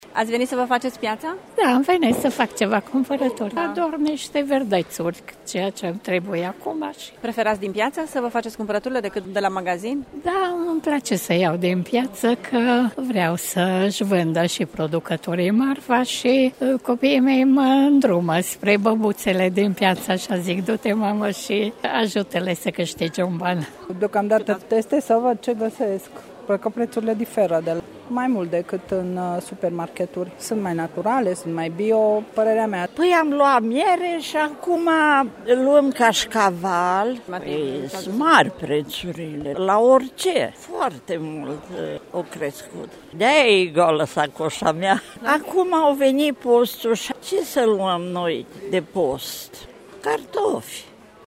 Târgumureșenii resimt scumpirile constante la toate alimentele, în ultimul timp și spun că preferă să cumpere din piață produse naturale pentru a ajuta producătorii locali: